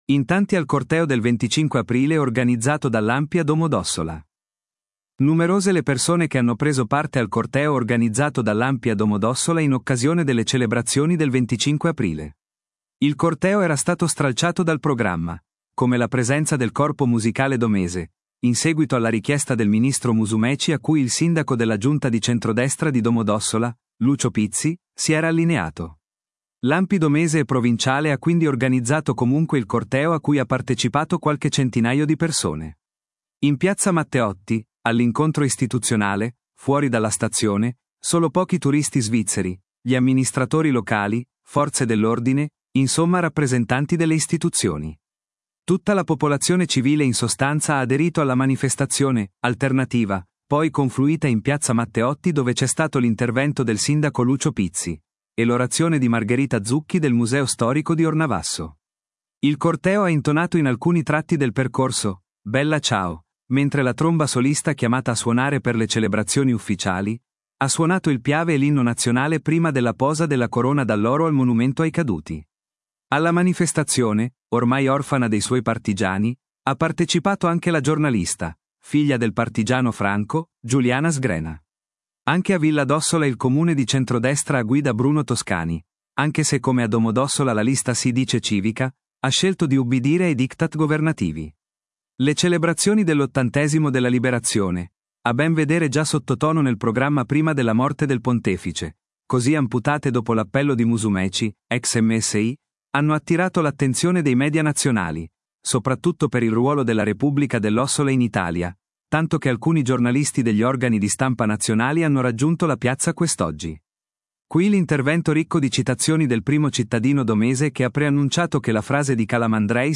Qui l’intervento ricco di citazioni del primo cittadino domese che ha preannunciato che la frase di Calamandrei sarà scritta su una targa affissa all’ingresso della sala storica della Resistenza a palazzo di città.